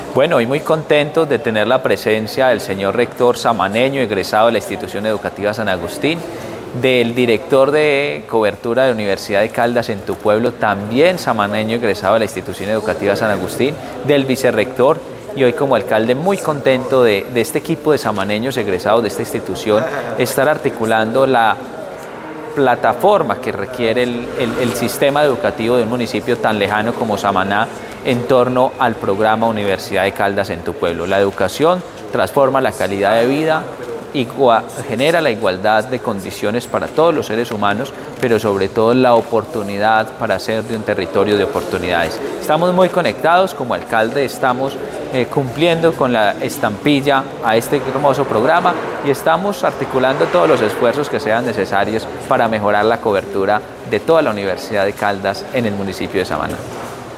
-Audio Alcalde de Samaná, Jorge Andrés Arango.
alcalde-samana-u-en-tu-pueblo.mp3